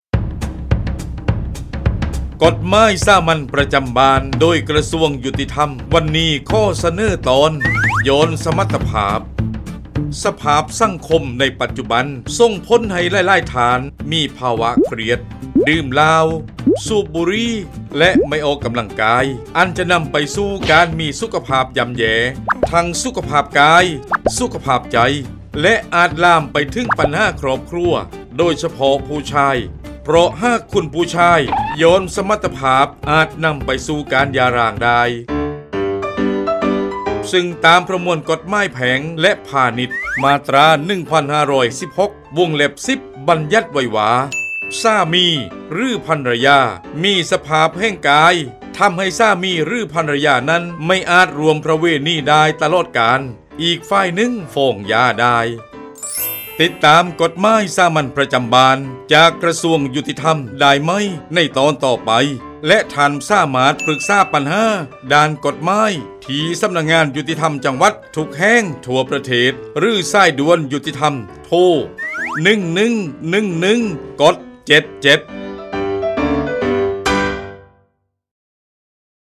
กฎหมายสามัญประจำบ้าน ฉบับภาษาท้องถิ่น ภาคใต้ ตอนหย่อนสมรรถภาพ
ลักษณะของสื่อ :   บรรยาย, คลิปเสียง